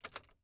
keys.wav